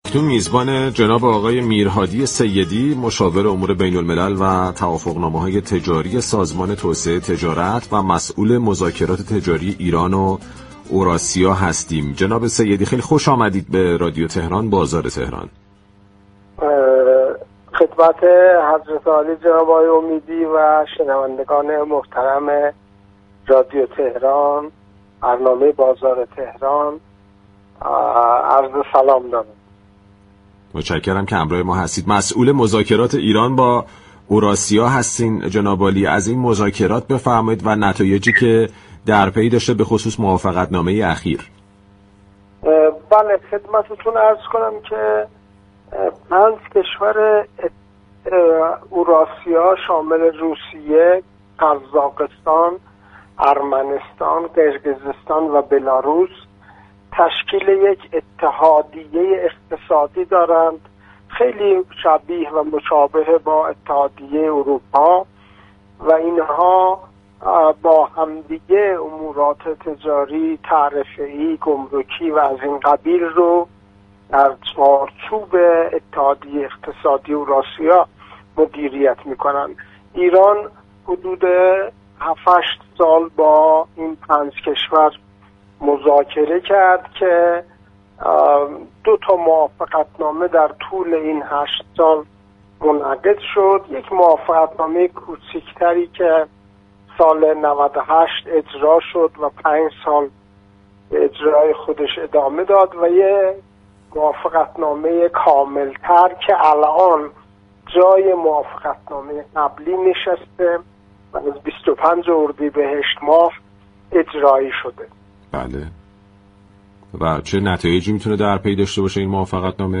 برنامه«بازار تهران»، روزهای شنبه تا چهارشنبه 11 تا 11:55 از رادیو تهران پخش می‌شود.